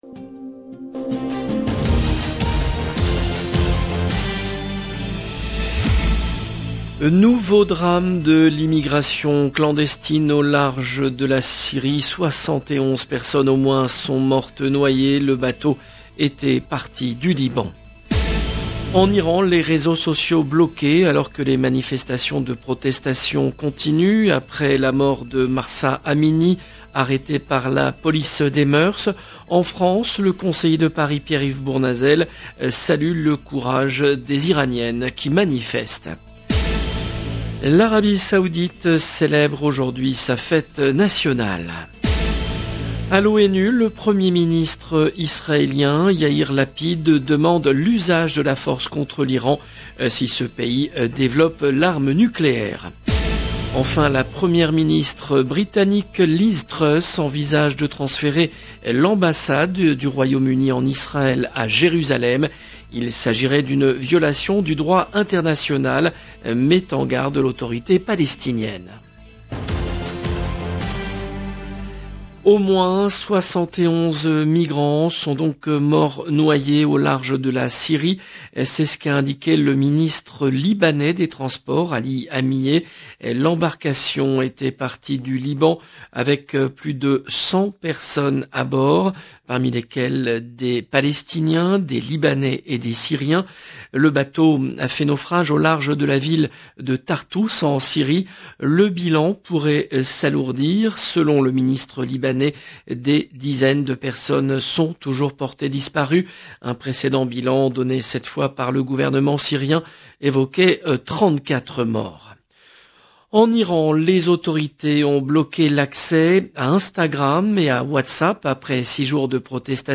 LE JOURNAL EN LANGUE FRANCAISE DE LA MI-JOURNEE DU 23/09/22